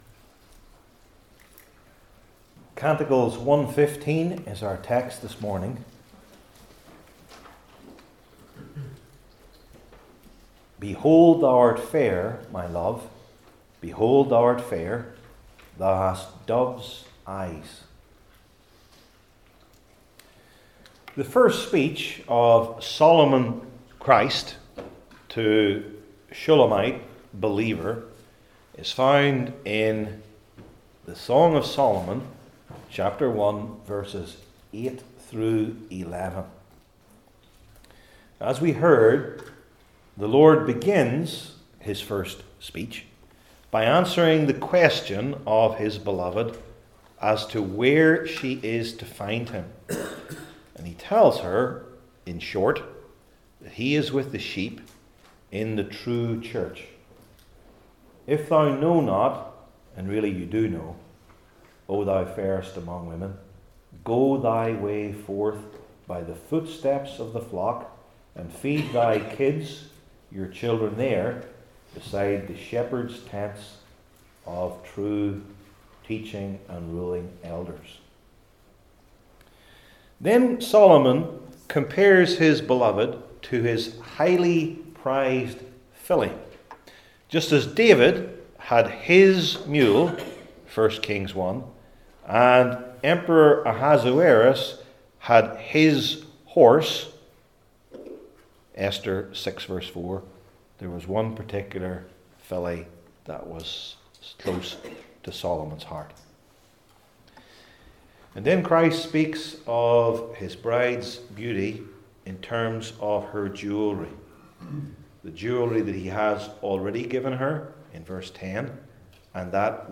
Old Testament Sermon Series I. The Endearing Address He Uses II. The Tender Adjective He Bestows III. The Beautiful Image He Employs